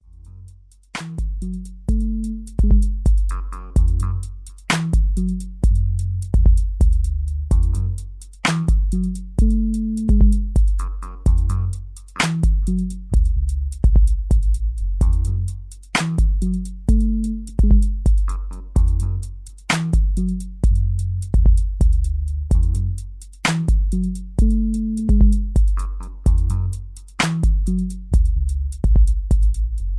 Mid Tempo RnB with a South Vibe